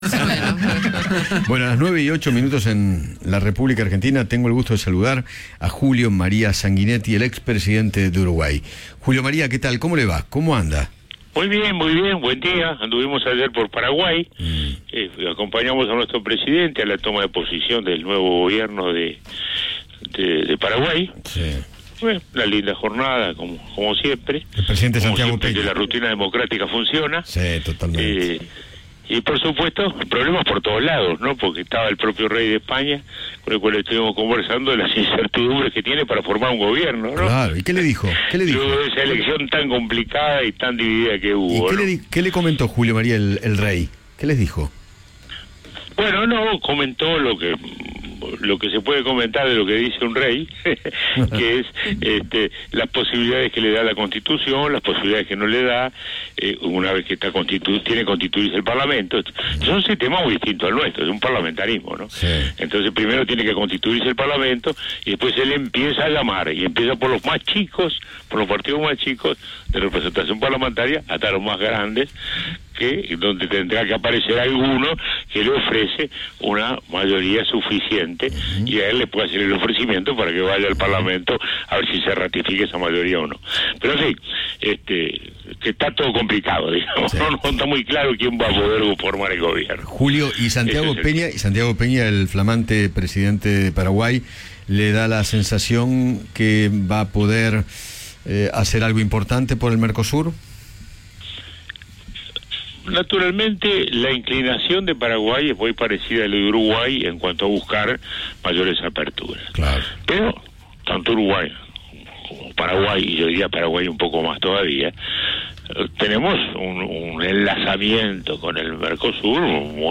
Juan María Sanguinetti, ex presidente de Uruguay, dialogó con Eduardo Feinmann sobre el impacto de las Elecciones PASO 2023 y se refirió a la contribución de Paraguay en el Mercosur.